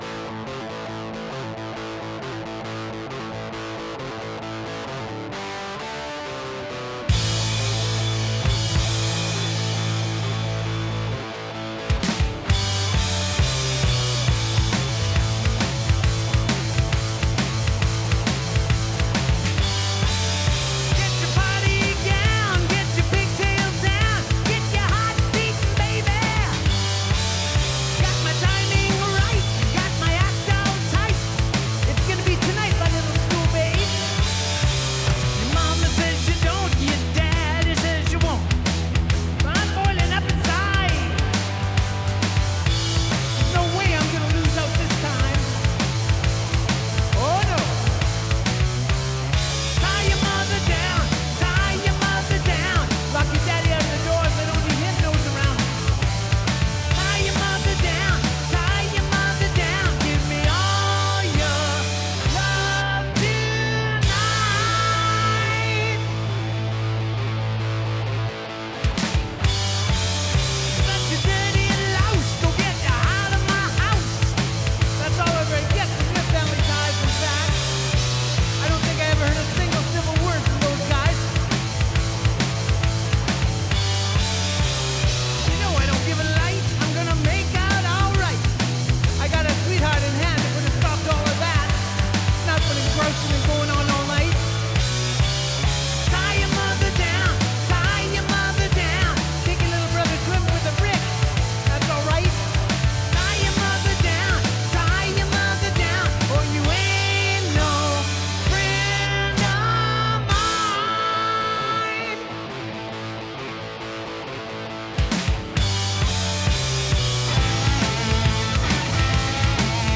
Added bass and remix